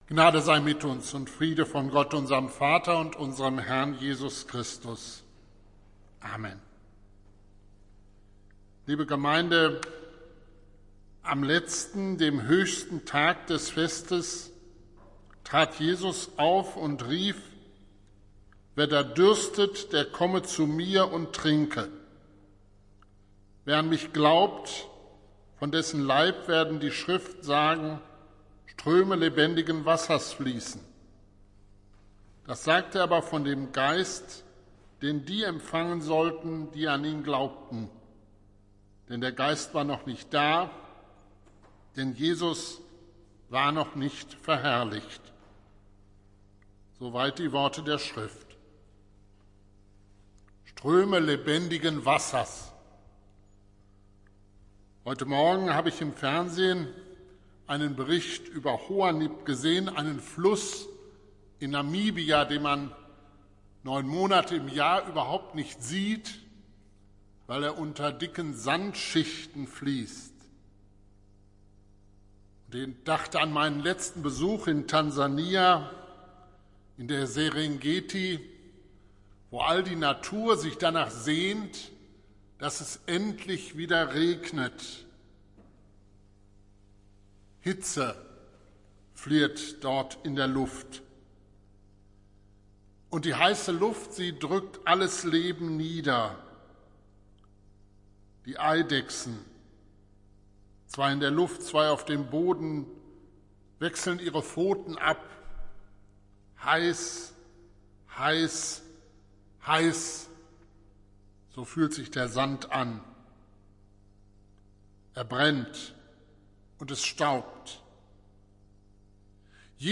Predigt des Gottesdienstes aus der Zionskirche vom Sonntag, den 16.05.2021